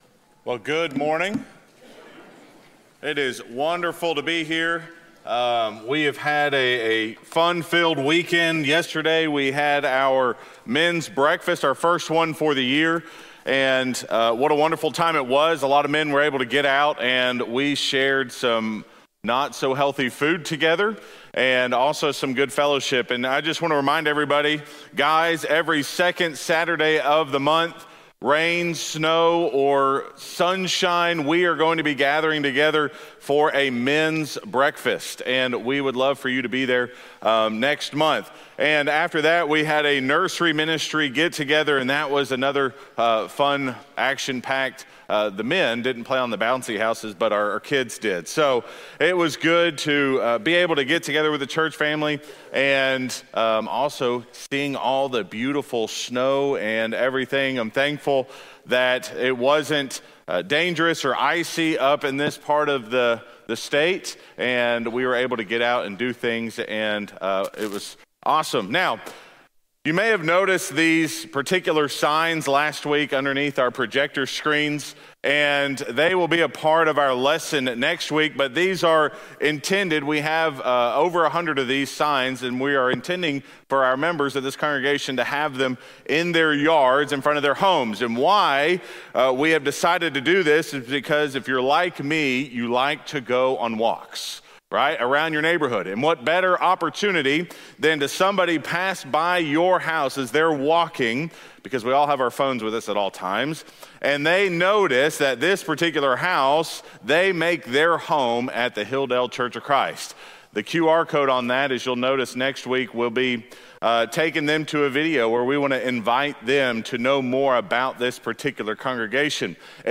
This podcast has been created to provide you with the audio from our weekly lessons. We hope that you will be encouraged and convicted by these truths that come from the Bible.